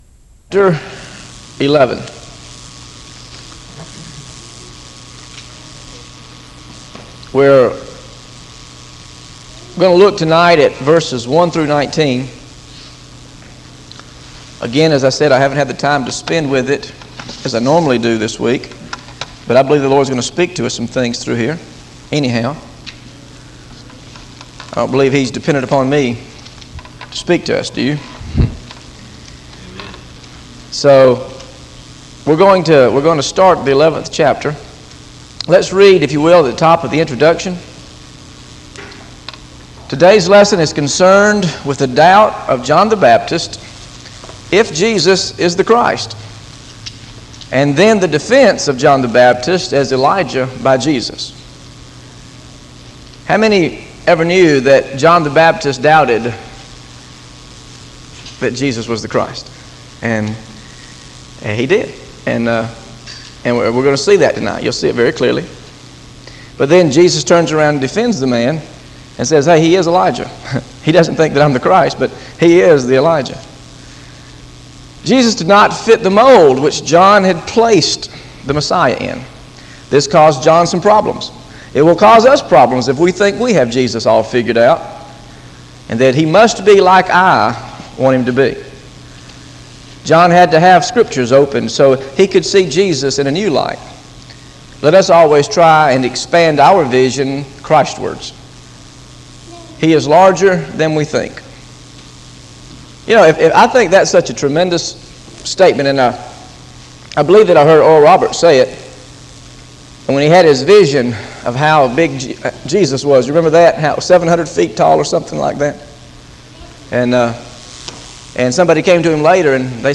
GOSPEL OF MATTHEW BIBLE STUDY SERIES This study of Matthew: Matthew 11 1-19 How to Avoid Limiting God With Our Theology is part of a verse-by-verse teaching series through the Gospel of Matthew.